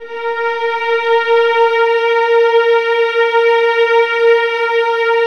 VIOLINS CN5.wav